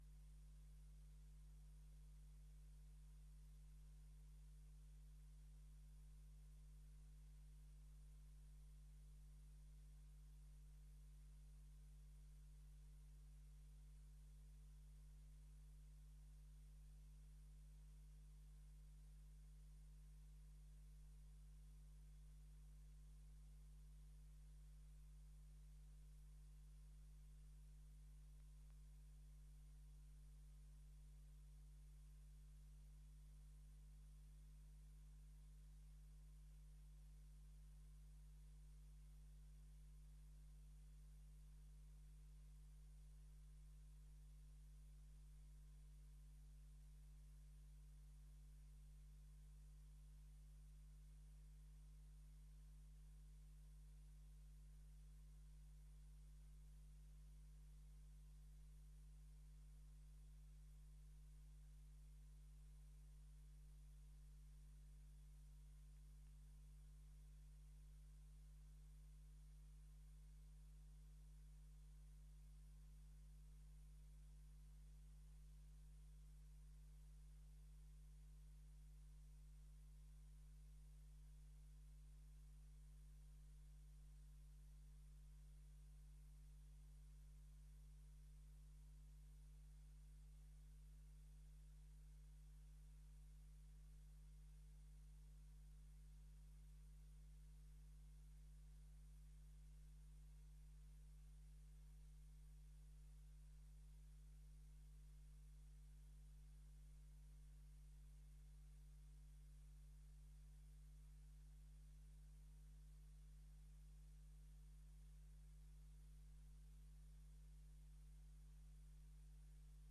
Raadscommissie 31 maart 2025 19:30:00, Gemeente Dalfsen
Download de volledige audio van deze vergadering
Locatie: Raadzaal